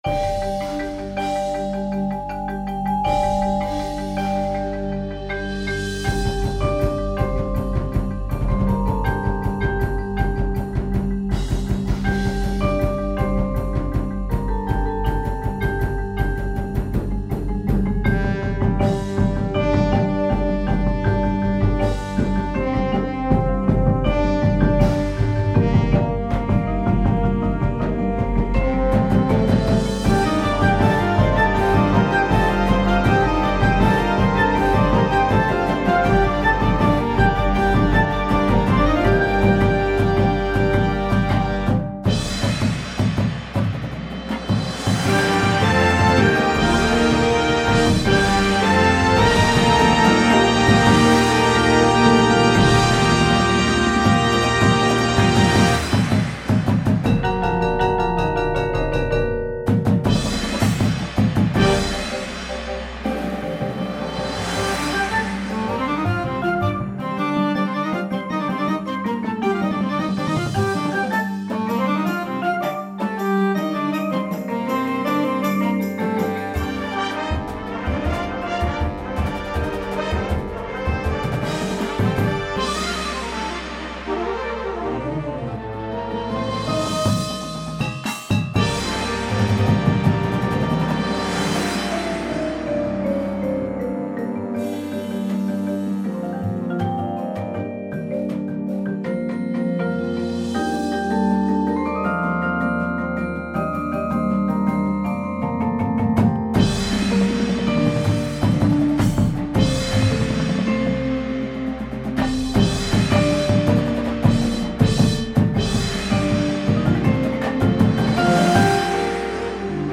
• Flute
• Horn in F
• Tuba
• Snare Drum
• Bass Drums